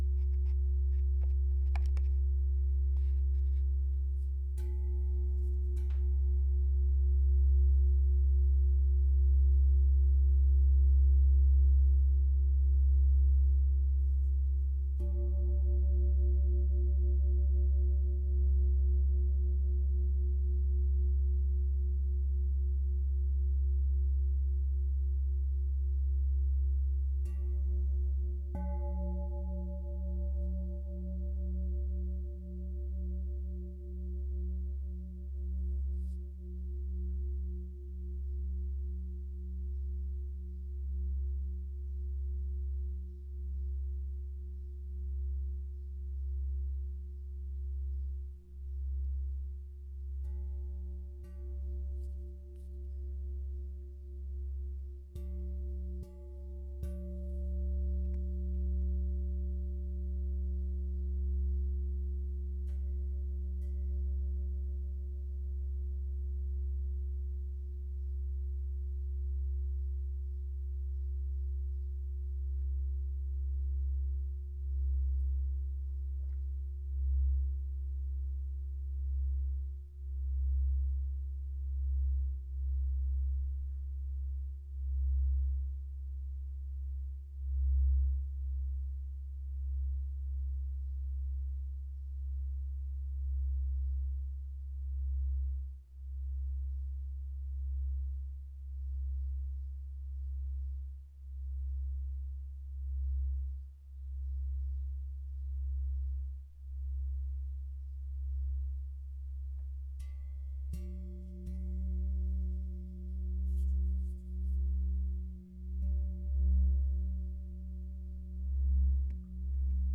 Grosser Röhrengong OM (60 mm Durchmesser)
Die grossen Röhrengongs zeichnen sich durch ihre obertonreiche Stimmung und den warmen sehr langen Nachklang aus.
Er hat einen Durchmesser von 60 mm, was einen tiefen, warmem und lang anhaltenden Raumklang möglich macht.
Es schwingen hier auch klar die Quinte Gis mit, wodurch der Klang noch viel räumlicher erscheint.
Die Frequenz liegt bei CIS = 34/68 hz/136 hz.
Je nach Schlägel und Anschlagpunkt variieren Klangfülle, Obertöne und auch die Grundtöne.
Roehrengong-Cis-60-x-25-mm-.wav